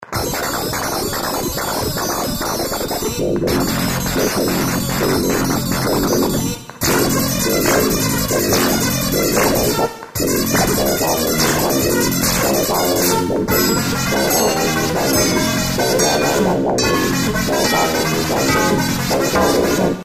the following sound samples were created using a single zeroscillator (the carrier) being modulated by a typical vco (the modulator) and/or combinations of lfos and envelope generators. with the exception of a vca and a tiny bit of reverb in some cases, there is no other processing of any kind in these recordings of the zeroscillator output. specifically, no filters were utilized.:
bluesmix - overdubbing three passes of zeroscillator voices with no filters!